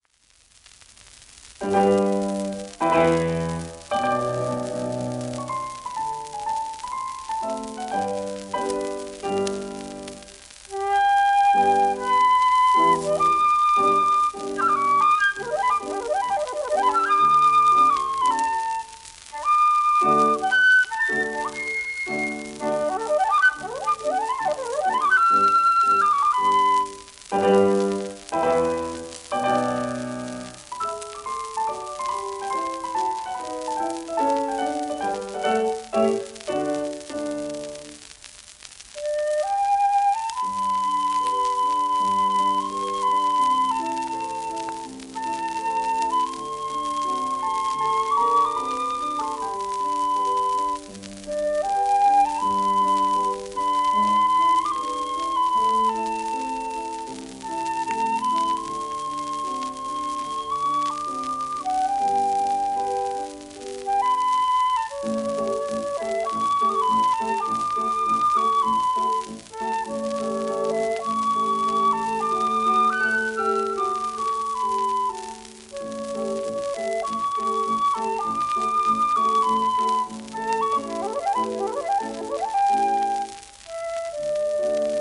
w/piano
イギリスのフルート奏者で、1914年から1938年にかけてイギリスの主要なオーケストラで活躍した。